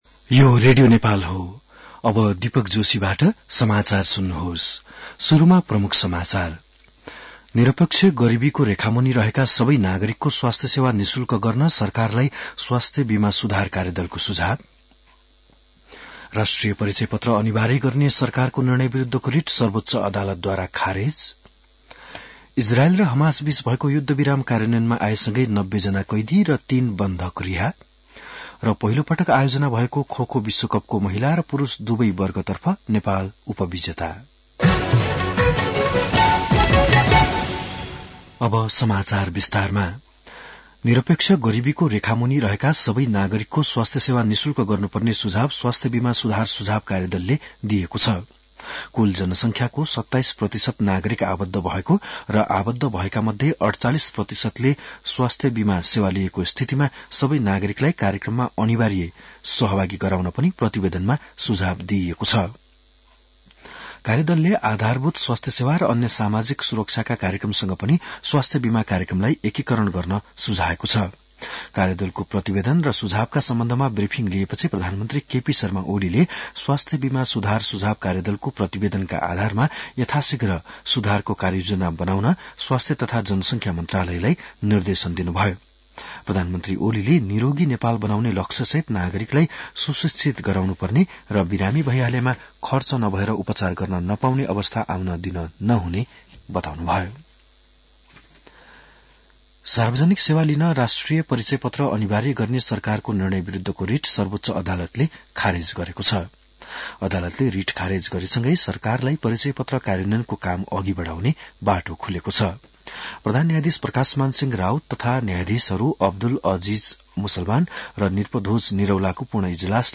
बिहान ९ बजेको नेपाली समाचार : ८ माघ , २०८१